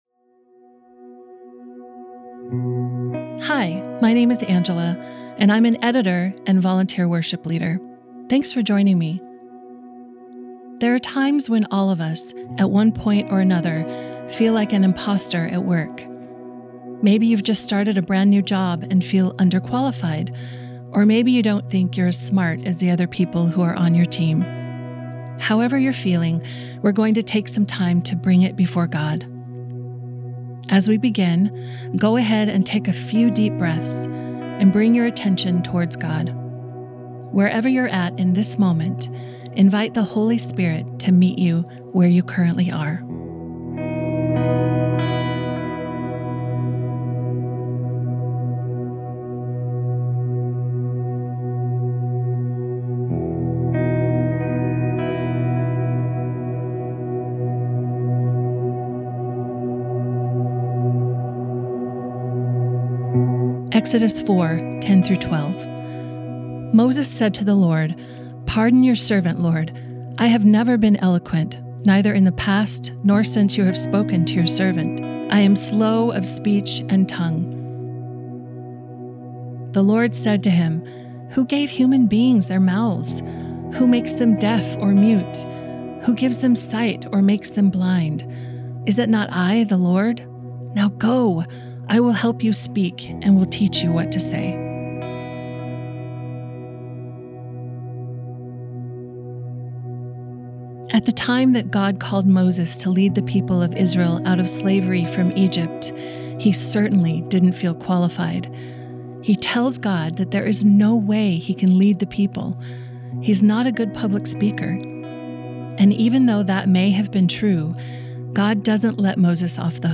AUDIO PRAYER GUIDES